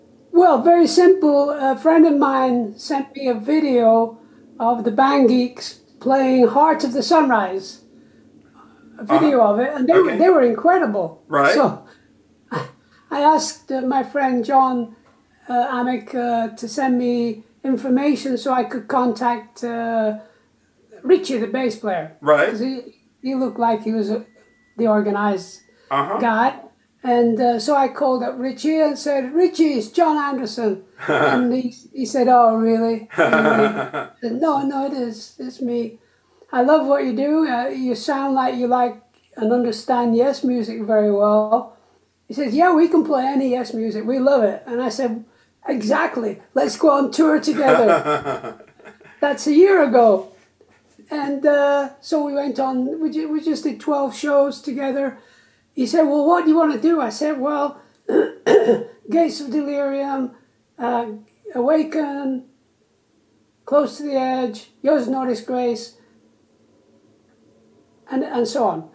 Interview with Jon Anderson, co-founder of the legendary rock band, Yes, about his new solo album with The Band Geeks called True.